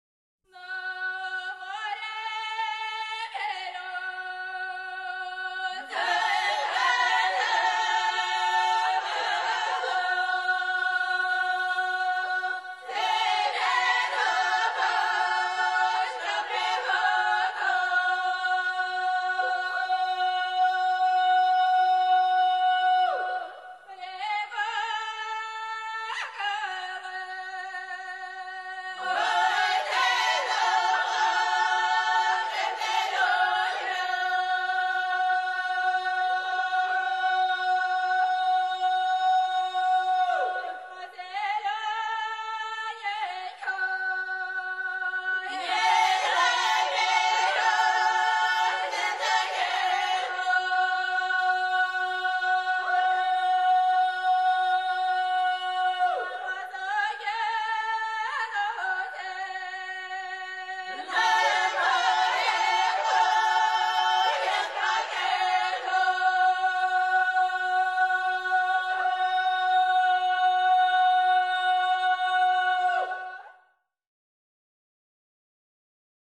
1."На море берёза"(народная) -